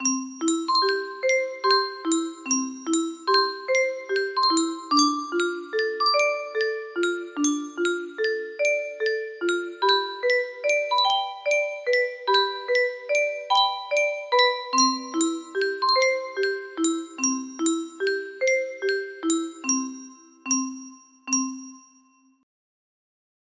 little prayer music box melody